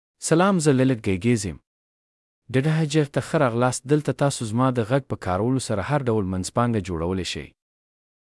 Gul NawazMale Pashto AI voice
Gul Nawaz is a male AI voice for Pashto (Afghanistan).
Voice sample
Male
Gul Nawaz delivers clear pronunciation with authentic Afghanistan Pashto intonation, making your content sound professionally produced.